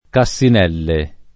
Cassinelle [ ka SS in $ lle ]